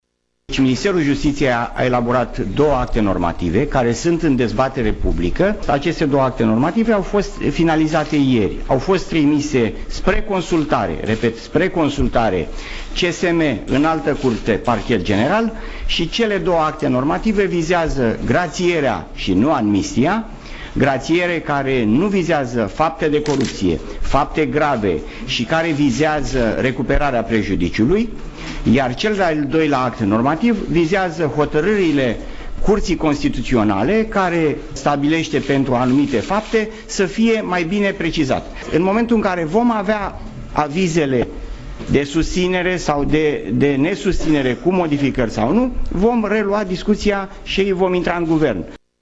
Ministrul Justiției, Florin Iordache, a subliniat, la rândul său, că cele două acte normative vizează grațierea, nu amnistia: